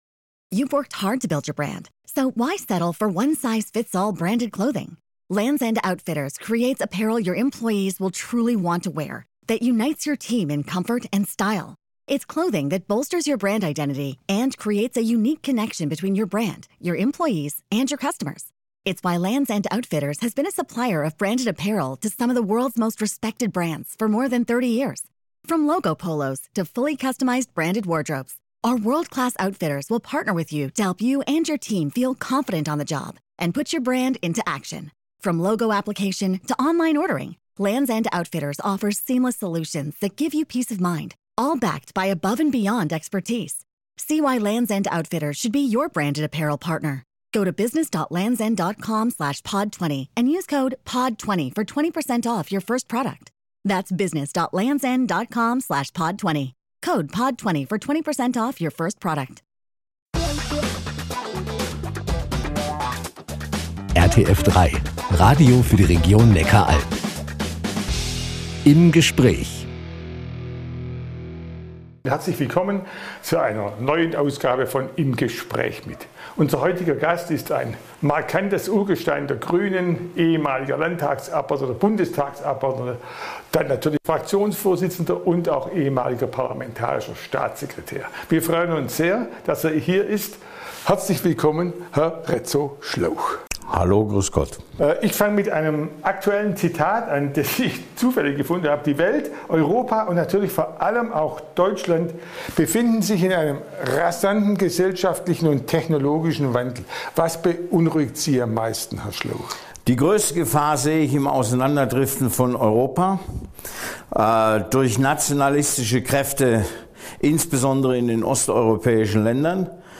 Im Gespräch mit Rezzo Schlauch, Politiker B90/Die Grünen | Reutlingen, Tübingen, Zollernalb ~ RTF1 Neckar-Alb Podcast | Reutlingen Tübingen Zollernalb Podcast